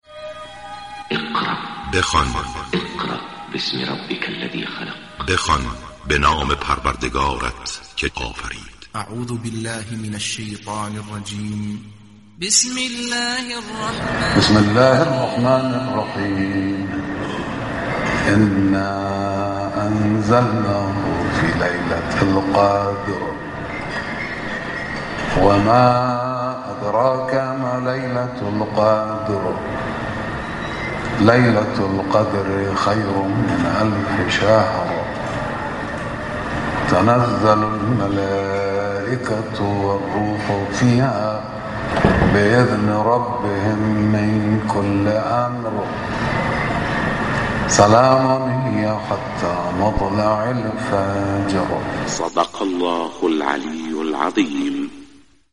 تلاوت سوره قدر
قرائت قرآن با صدای آیت الله خامنه‌ای